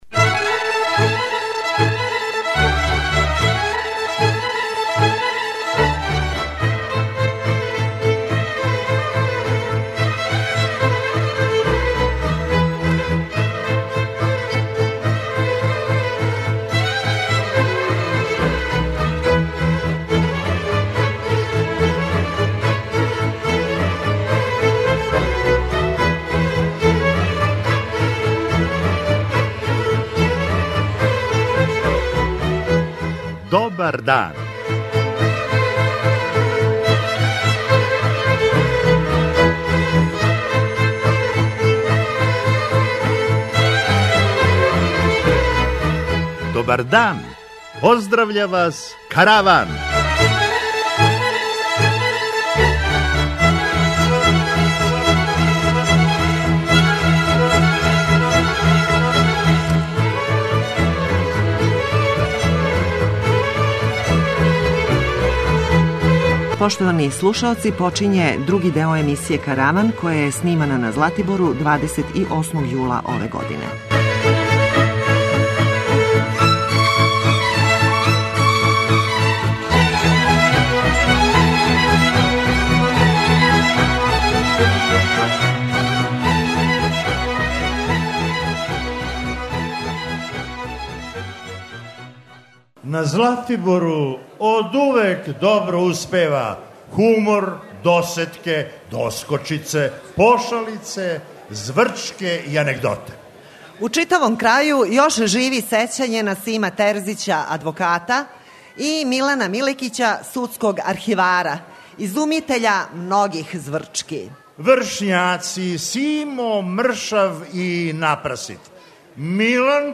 Још једно специјално издање Каравана, други део! Овога пута житељи Златибора имали су прилику да буду део јединственог и непоновљивог догађаја - присуствовали су јавном снимању Каравана, а ви сте данас у прилици да чујете управо тај златиборски Караван!
преузми : 24.87 MB Караван Autor: Забавна редакција Радио Бeограда 1 Караван се креће ка својој дестинацији већ више од 50 година, увек добро натоварен актуелним хумором и изворним народним песмама.